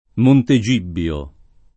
Montegibbio [ monte J& bb L o ]